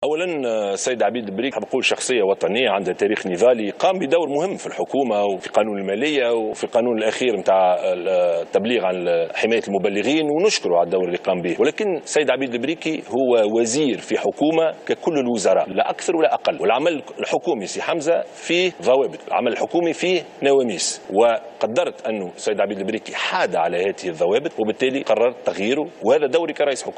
قال رئيس الحكومة يوسف الشاهد في حوار خاص اليوم الأحد إن العمل الحكومي له ضوابط وقوانين ونواميس مضيفا أنه قدر أن عبيد البريكي حاد عن نواميس وضوابط العمل الحكومي ولذلك أقاله.
وأضاف رئيس الحكومة في حوار خاص على قناة الحوار التونسي أن عبيد البريكي عضو من أعضاء الحكومة كغيره وماحدث معه يمكن أن يحدث مع أي مسؤول في الدولة ولإعادة هيبة الدولة التي ينتظرها كل التونسيين يجب أن يتم التصرف على هذا النحو وفق قوله.